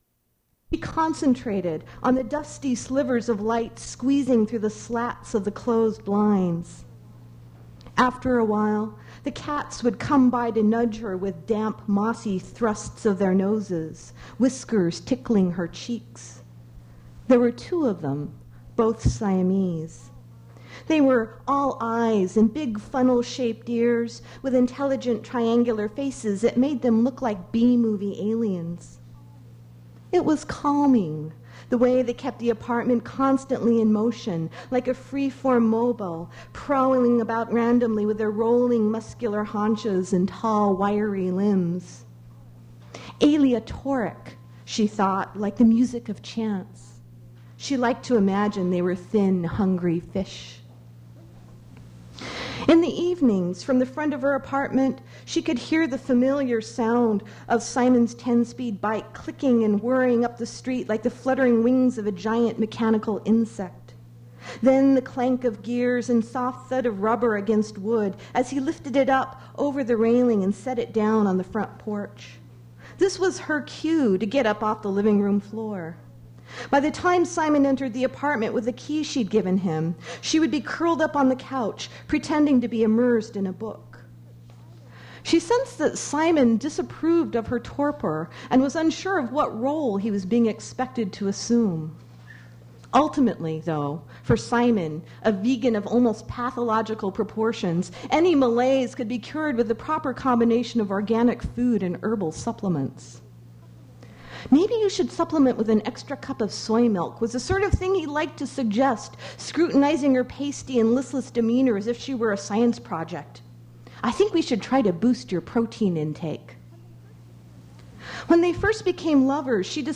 Source mp3 edited access file was created from unedited access file which was sourced from preservation WAV file that was generated from original audio cassette. Language English Identifier CASS.840 Series River Styx at Duff's River Styx Archive (MSS127), 1973-2001 Note Intro and beginning of reading missing, audio abruptly starts in the middle of a story.